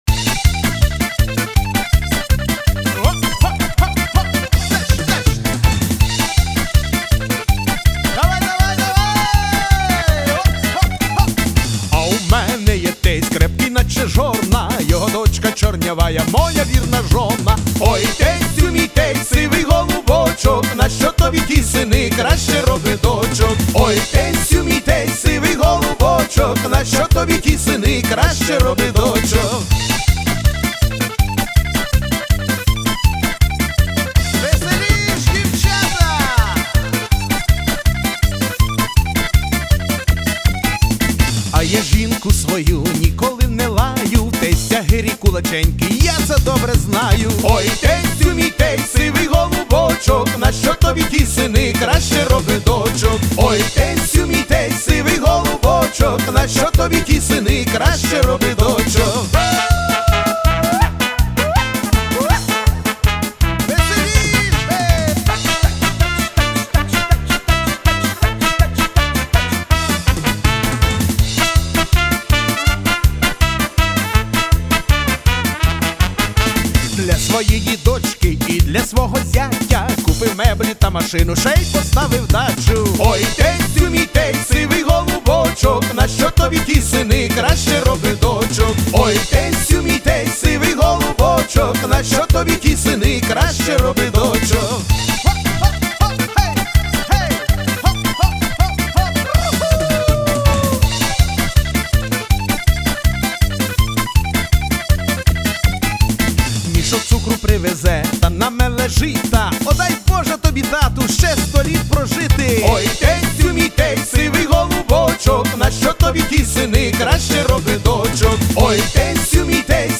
Весела пісня про улюбленого тестя і його красиву дочку.